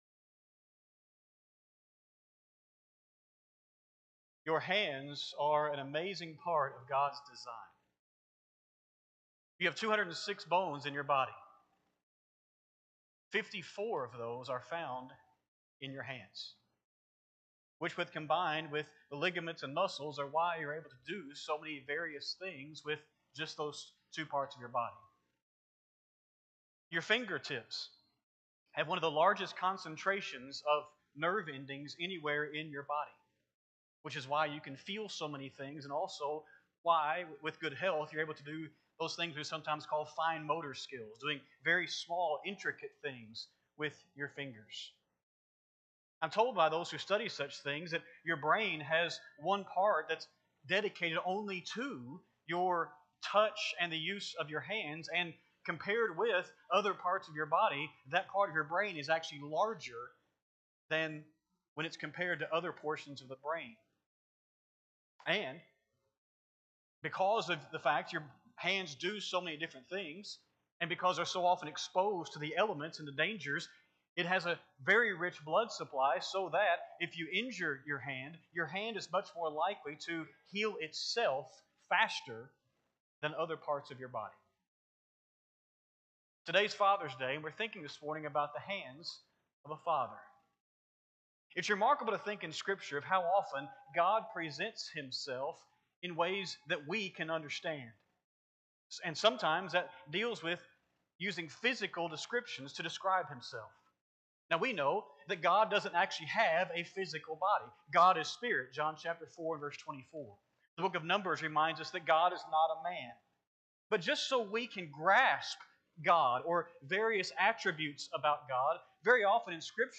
6-15-25-Sunday-AM-Sermon.mp3